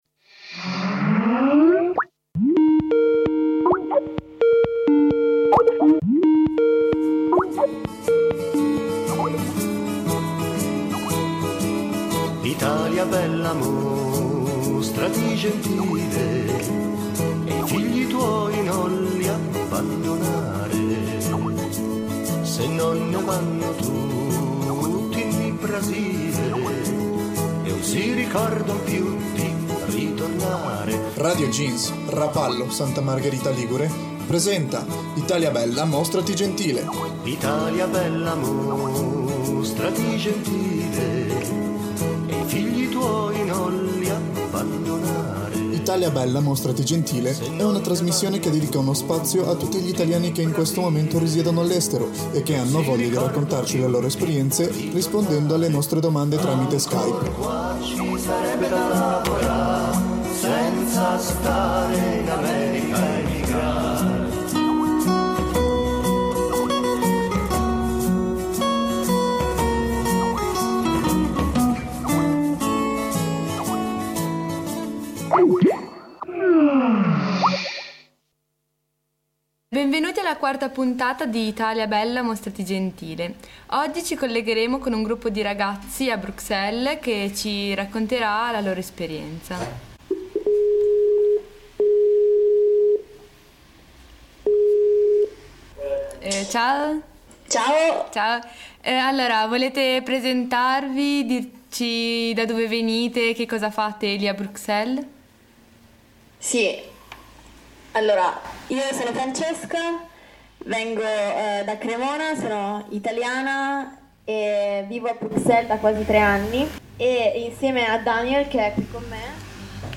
Storie personali e ampie riflessioni si aprono attorno alle voci che provengono da Skype e che noi raccogliamo.